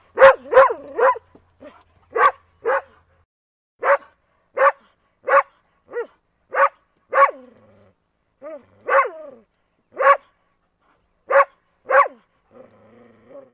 На этой странице собраны звуки собак, играющих с игрушками: лай, повизгивание, рычание и другие забавные моменты.
Звук игры хозяина с псом он кидает ему